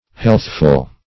Healthful \Health"ful\ (-f[.u]l), a.